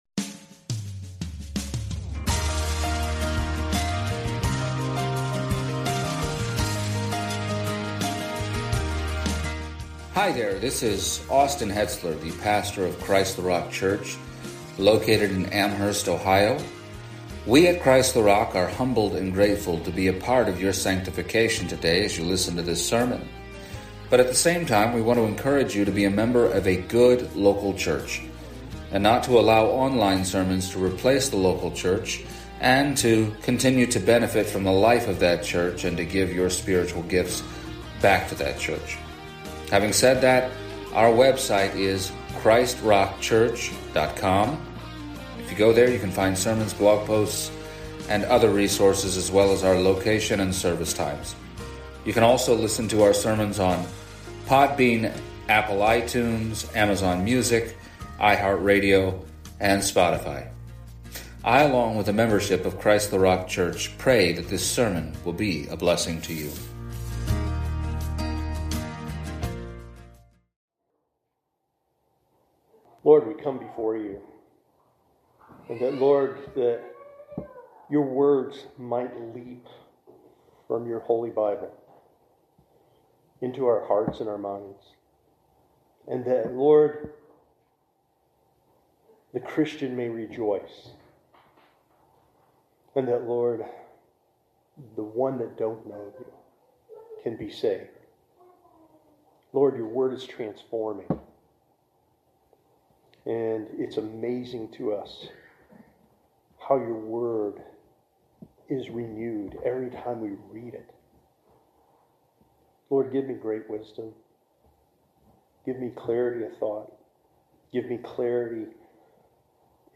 Romans 12:1-3 Service Type: Sunday Morning Where do your affections lie?